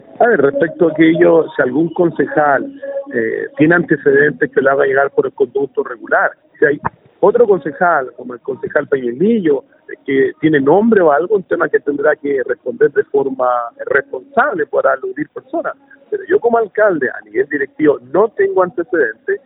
El alcalde de Penco, Rodrigo Vera, dijo que aún no han sido notificados por parte de la Contraloría respecto de esta situación y menos se les ha solicitado información respecto al caso.